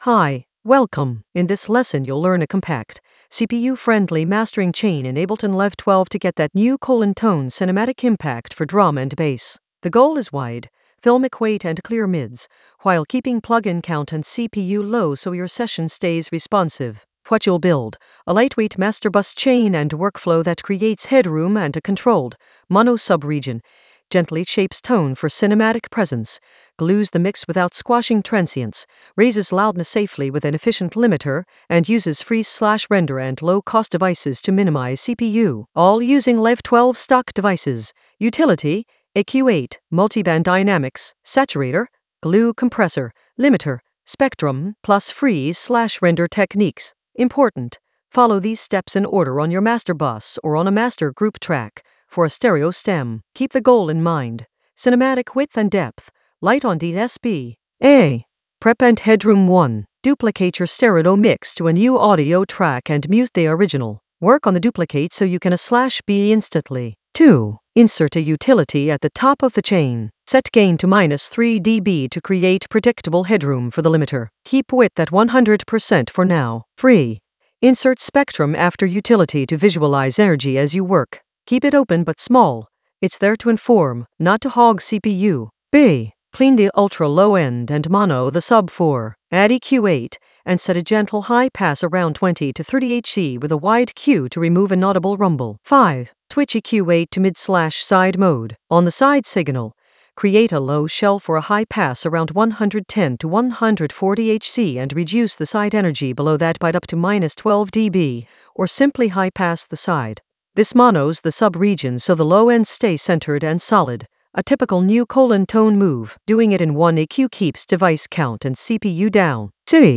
An AI-generated beginner Ableton lesson focused on Nu:Tone cinematic impact in Ableton Live 12 with minimal CPU load in the Mastering area of drum and bass production.
Narrated lesson audio
The voice track includes the tutorial plus extra teacher commentary.